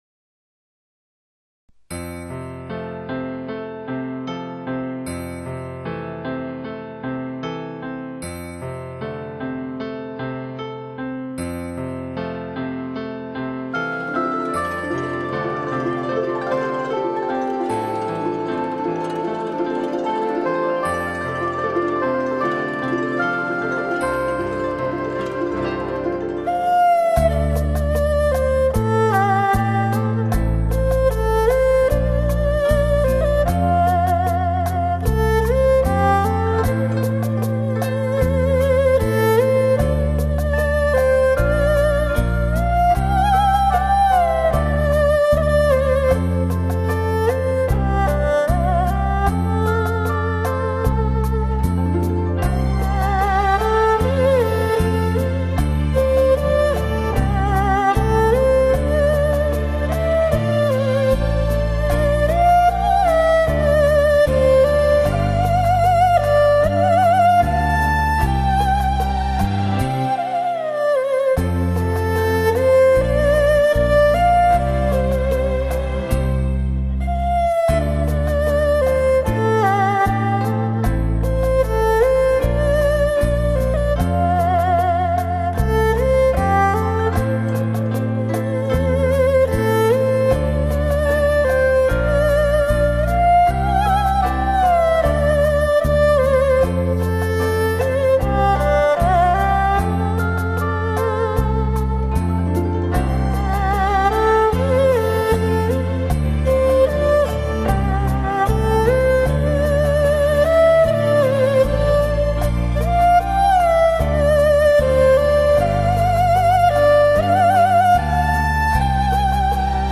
以新的方式诠释上一代的流行歌曲
试听为低品质wma，下载为320k/mp3